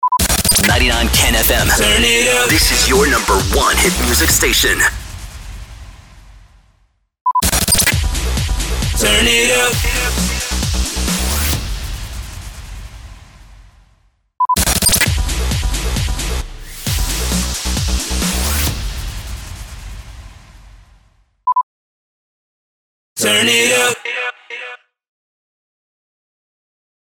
315 – SWEEPER – TURN IT UP
315-SWEEPER-TURN-IT-UP.mp3